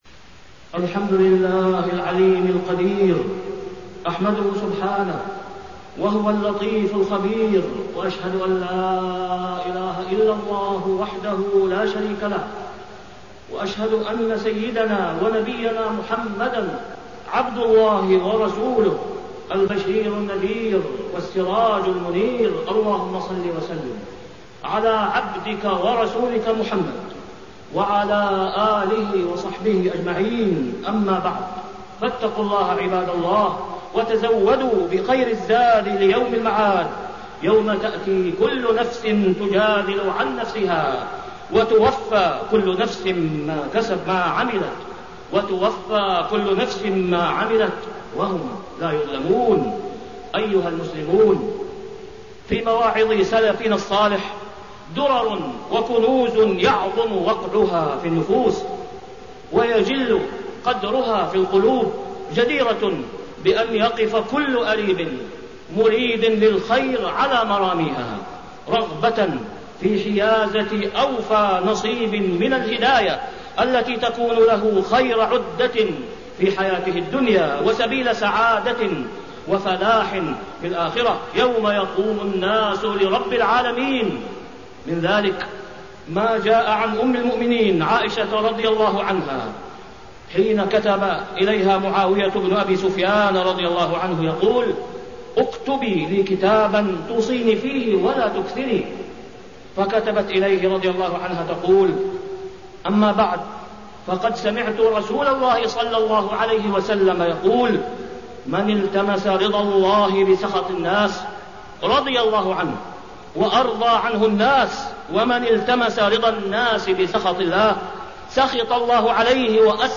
تاريخ النشر ١٥ محرم ١٤٣١ هـ المكان: المسجد الحرام الشيخ: فضيلة الشيخ د. أسامة بن عبدالله خياط فضيلة الشيخ د. أسامة بن عبدالله خياط ذم إيثار رضا المخلوق على رضا الخالق